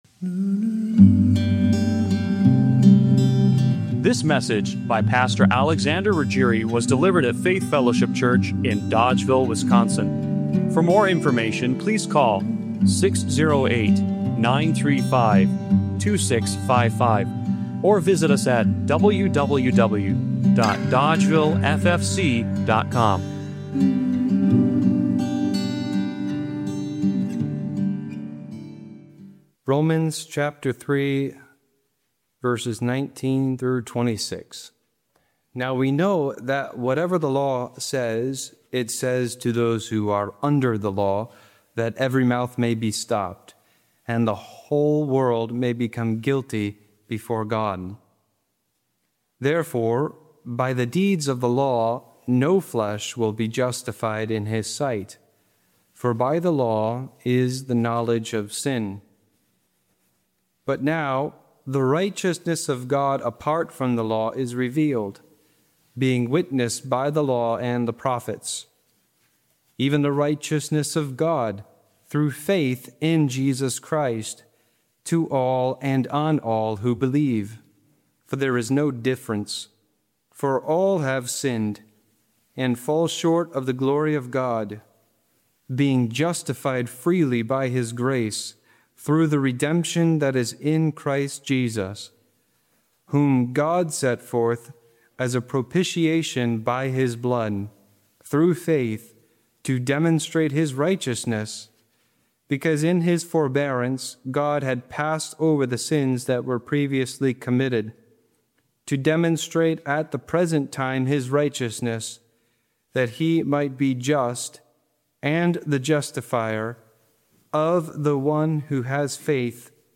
Romans 3:21-26 Service Type: Special Occasion Have you ever seen blood and wondered what it was really trying to say?
Good_Friday_2026_Spilled-and-Sprinkled-Blood.mp3